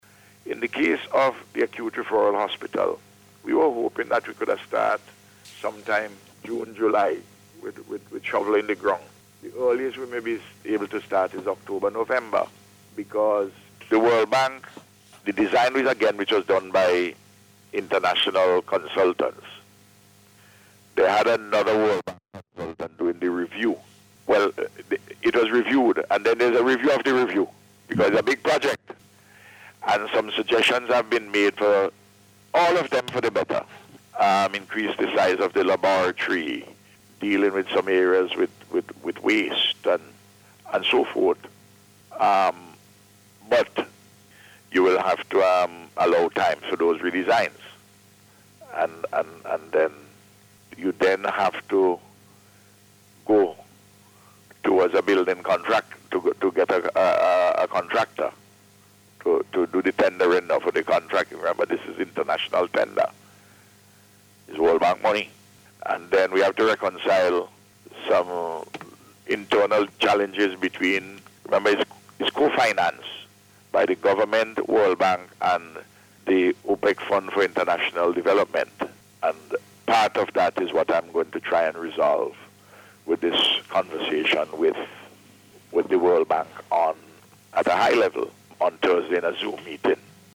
This is according to Prime Minister, Dr. Ralph Gonsalves as he provided an update on several projects on NBC Radio yesterday.